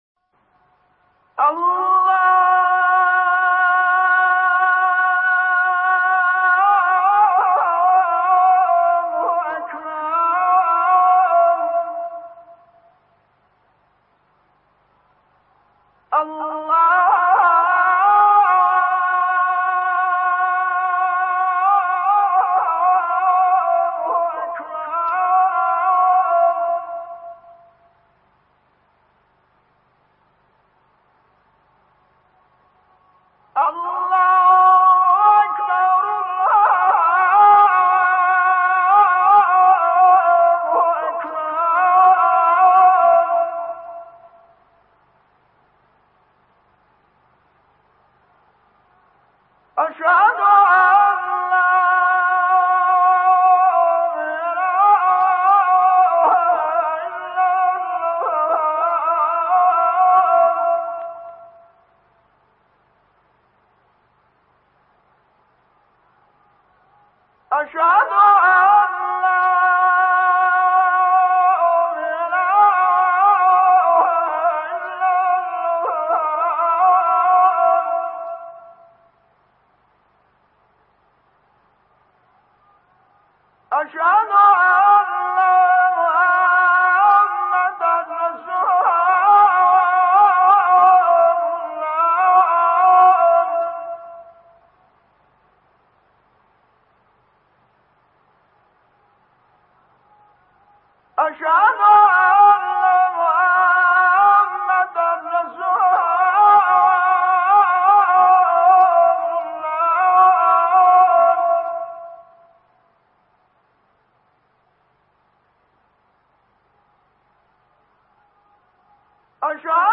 این اذان در سال ۱۳۳۴ در دستگاه بیات ترک ضبط شد و به دلیل لحن منحصر‌به‌فرد و حال و هوای معنوی‌اش، به‌عنوان اثری ماندگار در تاریخ اذان‌گویی ایران ثبت شده است. بیات ترک که از دستگاه‌های حزن‌انگیز موسیقی ایرانی است، با حال و هوای معنوی اذان هماهنگی ویژه‌ای دارد و همین امر باعث شده است که این اثر، تأثیری عمیق بر شنوندگان بگذارد.